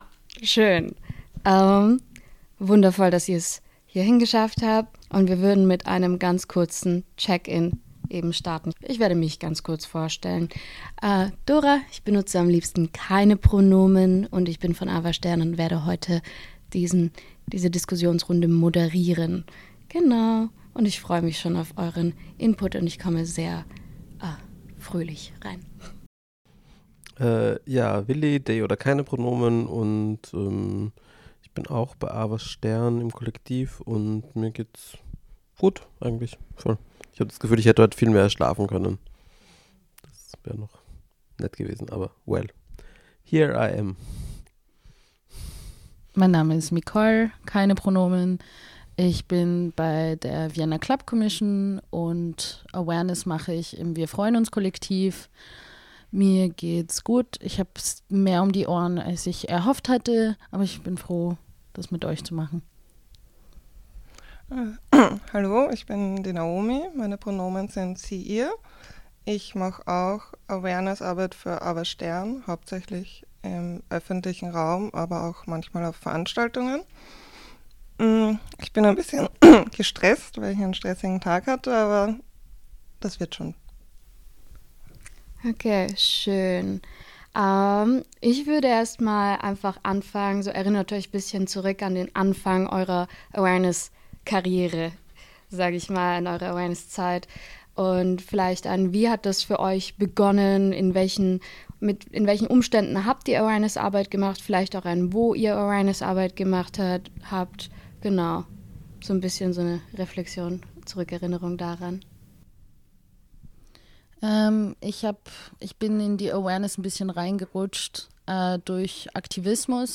Diskussion_ZukunftAwareness-1.mp3